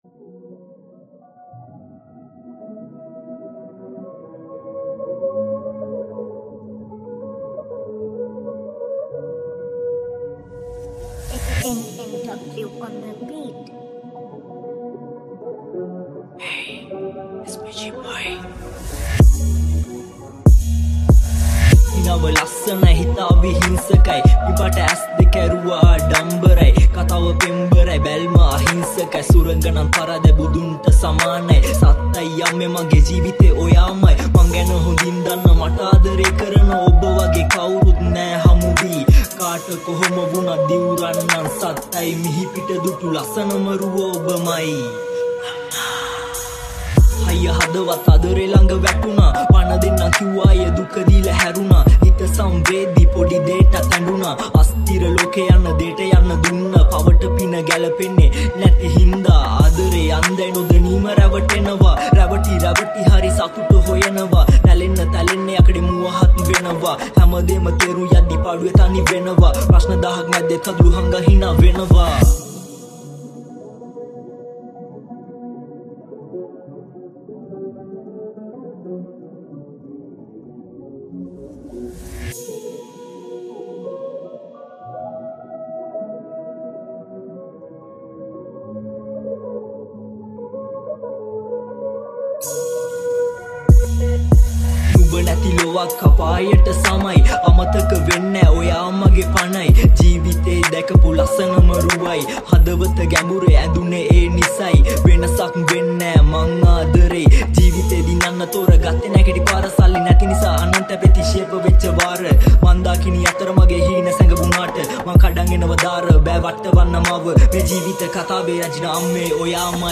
New Sinhala Rap